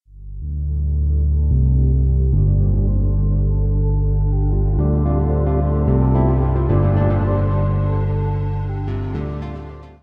Then there is the top notch music.